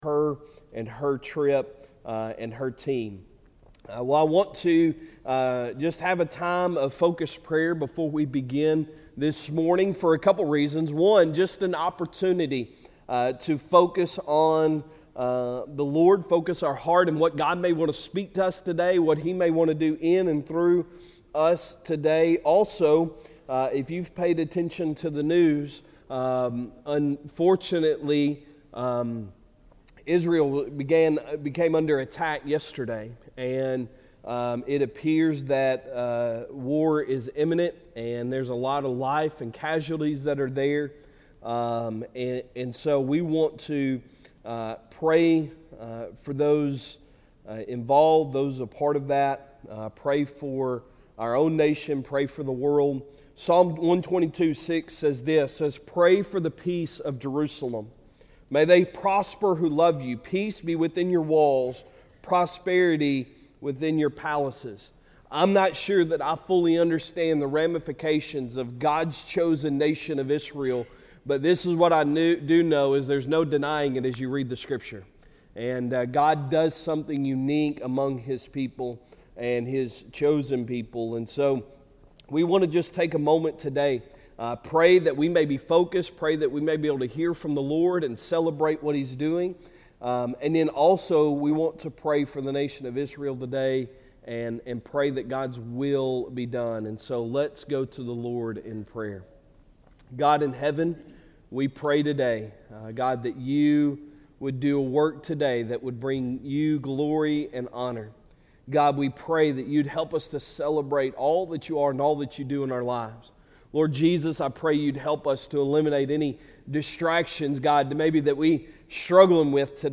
Sunday Sermon October, 8, 2023